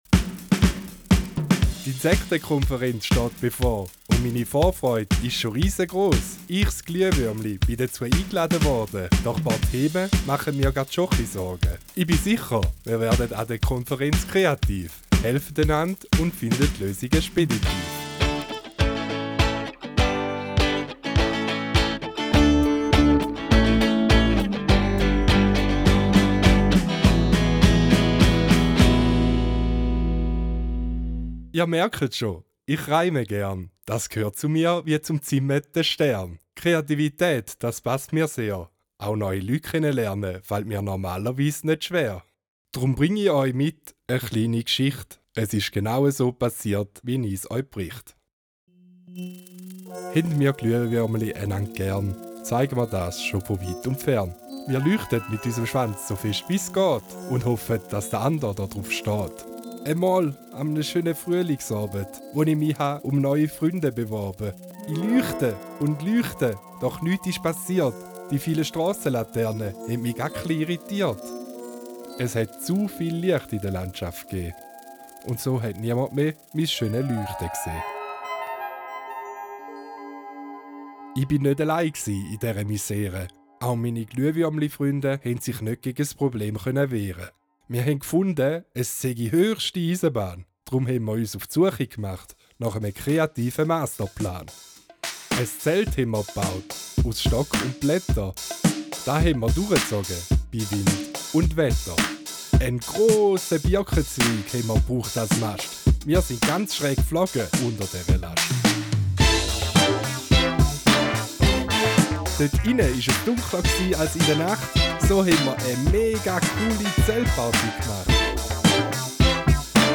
LS Posten Feuer Am Posten Feuer erzählt euch Glühwürmchen Eldfluga eine Geschichte aus ihrem Leben. Die TN lernen dabei die Schwierigkeiten von Feuer und Licht für die Natur kennen. Startet mit dem Hörspiel: Hört das Hörspiel über eure Lautsprecher.